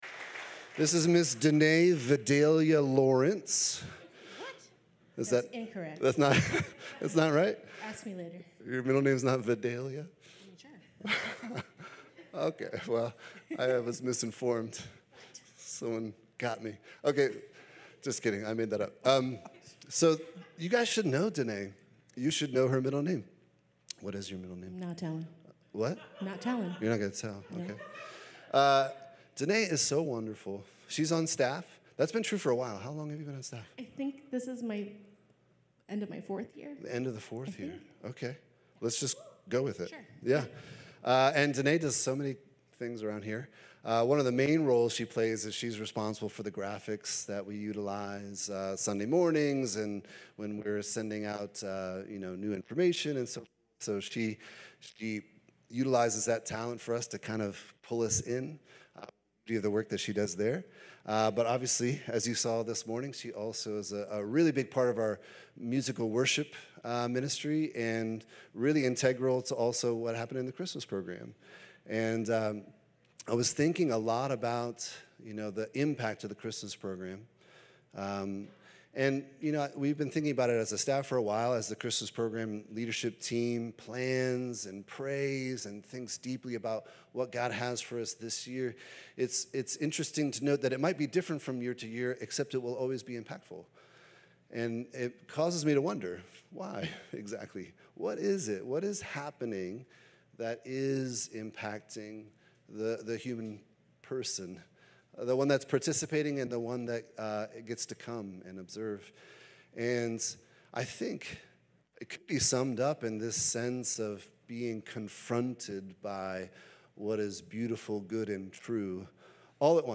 interview
message